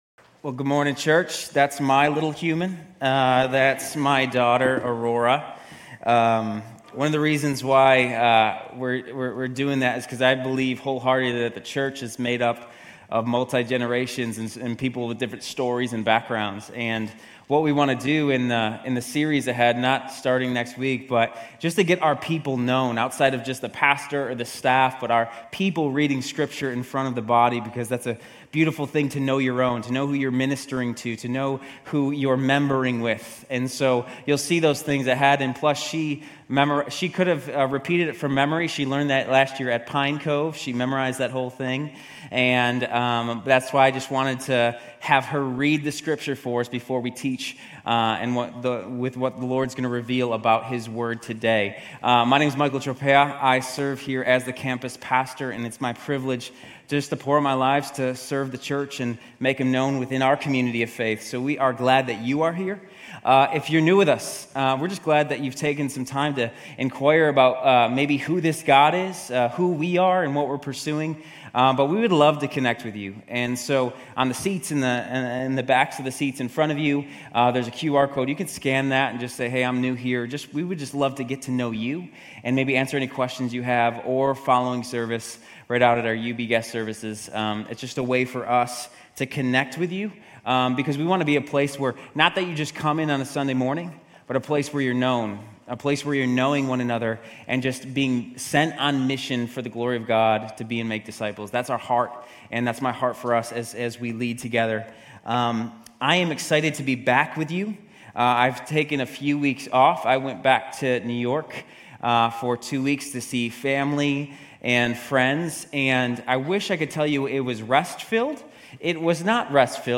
Grace Community Church University Blvd Campus Sermons Psalm 23 - Confidence Jul 15 2024 | 00:34:49 1x 00:00 / 00:34:49 Subscribe Share RSS Feed Share Link Embed